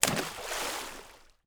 SPLASH_Small_04_mono.wav